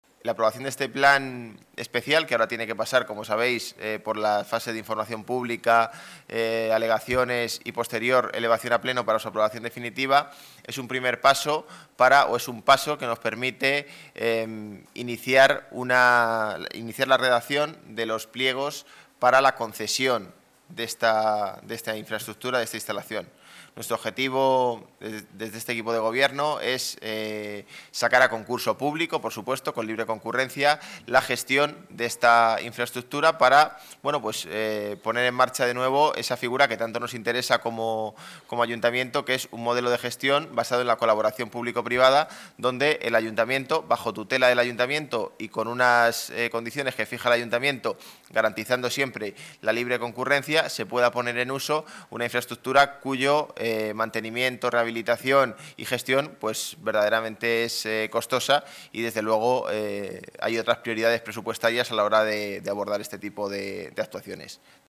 Nueva ventana:José Manuel Calvo, delegado Área Desarrollo Urbano Sostenible. Beti Jai concurso público